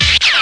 instruments
1 channel
scratchmore.mp3